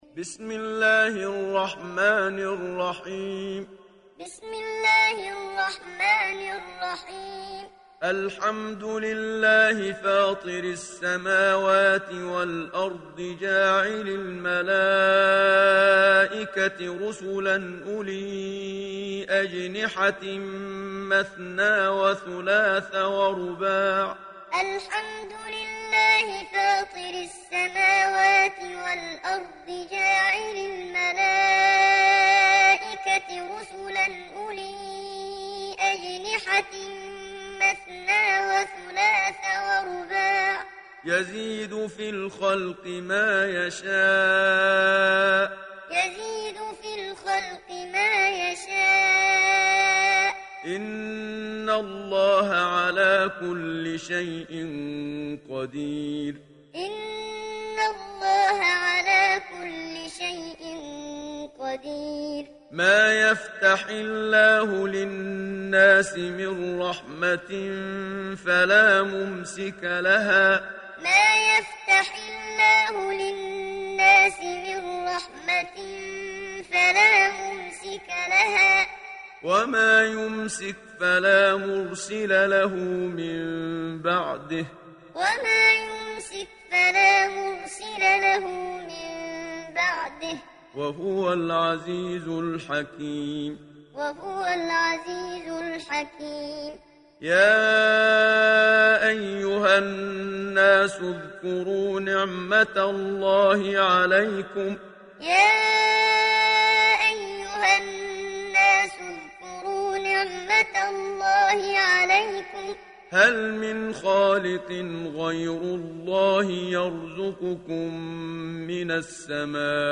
Download Surah Fatir Muhammad Siddiq Minshawi Muallim